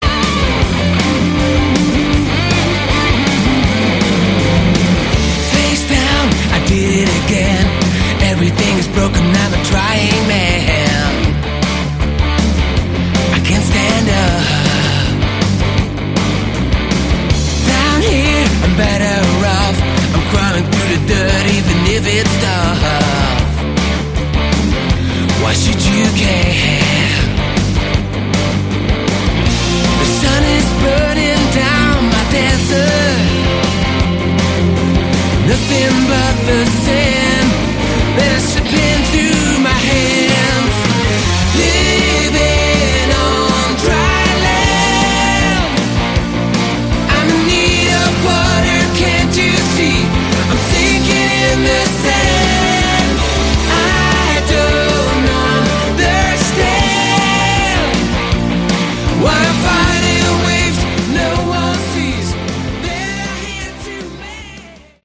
Category: AOR
lead and backing vocals
acoustic, electric guitars, keyboards
drums, percussion, synthesizers, keyboards